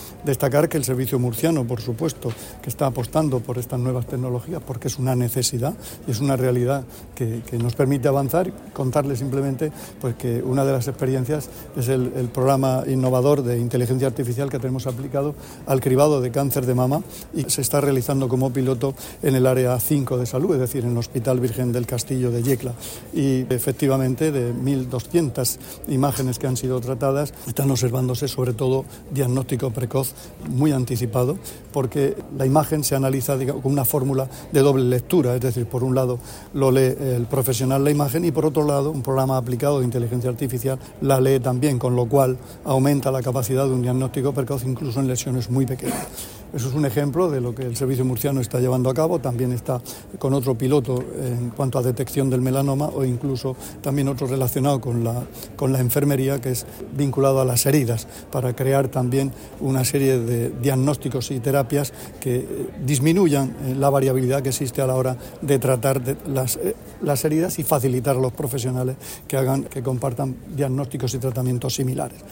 Sonido/ Declaraciones del consejero de Salud, Juan José Pedreño, sobre el uso de la inteligencia artificial en el SMS [mp3].